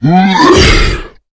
spawners_mobs_balrog_death.2.ogg